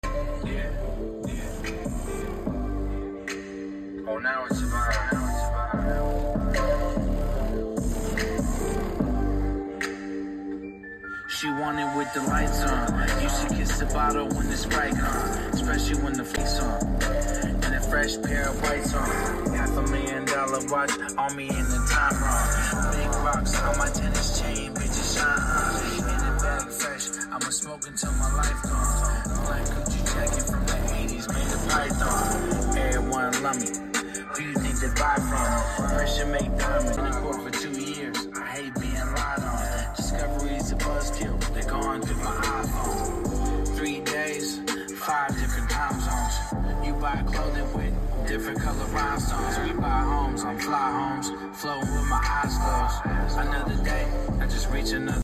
2 15”sundown zv6 8 8”ds18 8 1”deafbonce 18 speakers sound effects free download